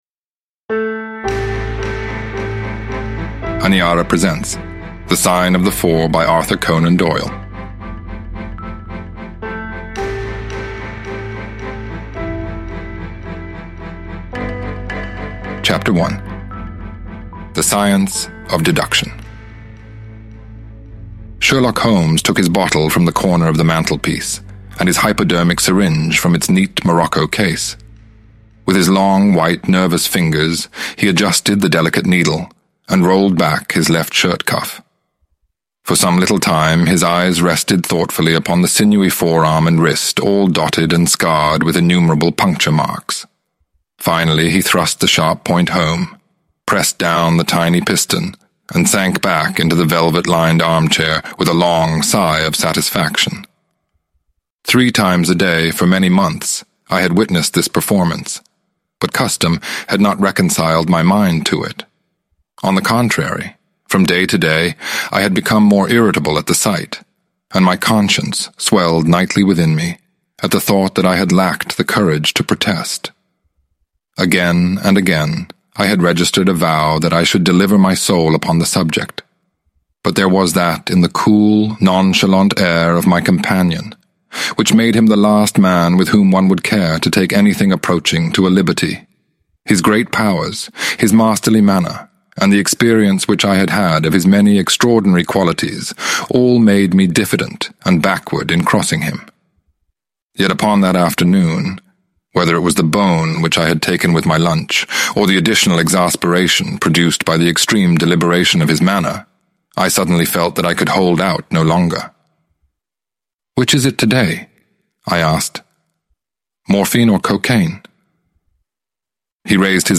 The Sign of Four – Ljudbok